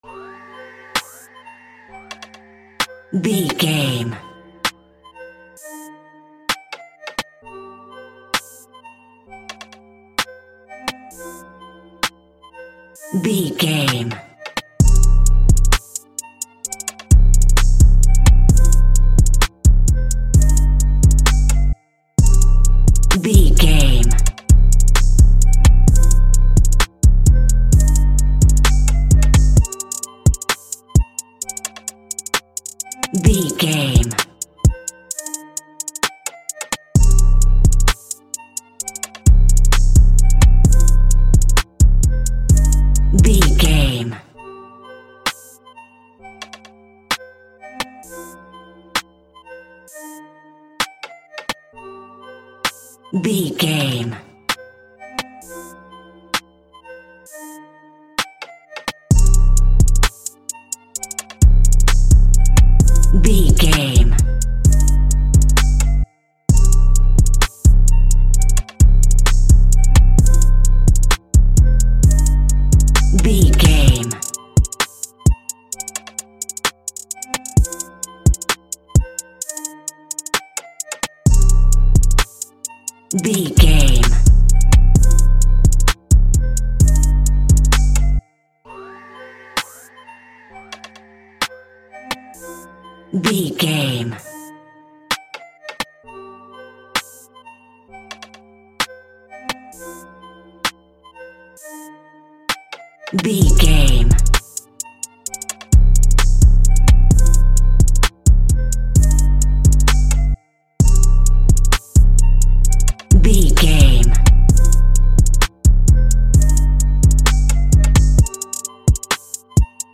Ionian/Major
aggressive
intense
driving
bouncy
energetic
dark
drums